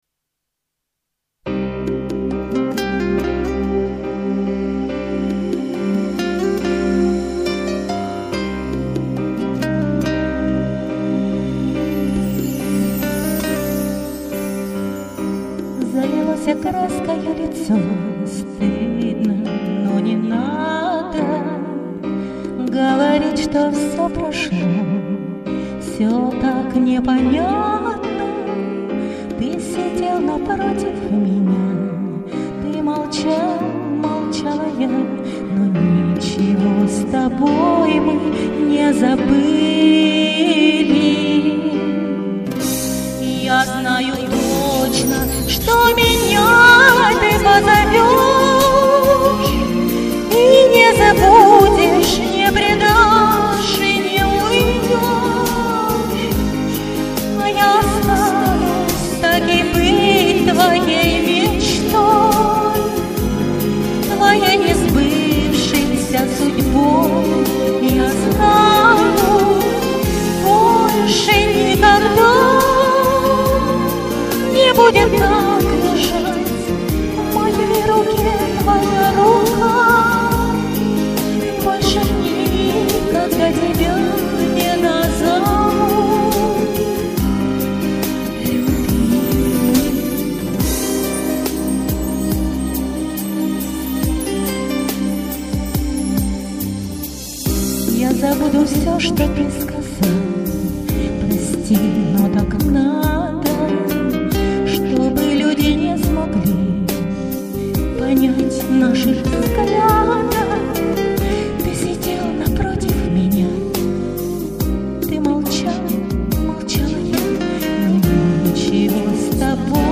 Качество записи может и разное!!!